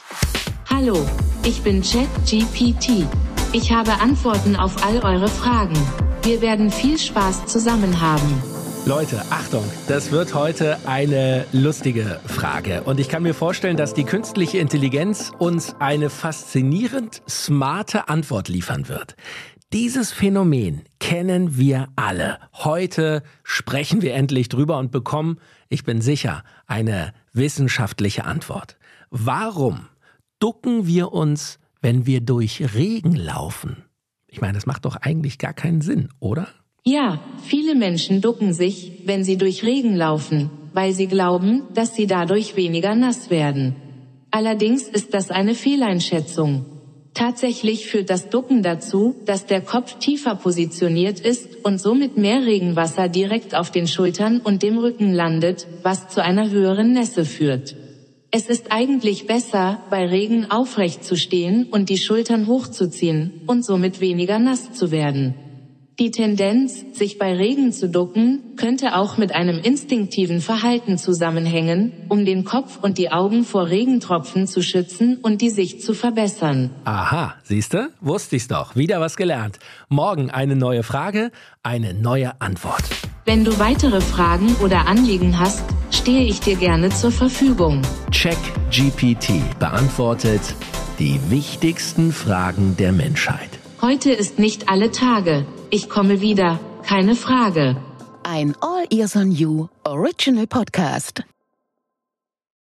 Künstlichen Intelligenz ChatGPT von OpenAI als Co-Host.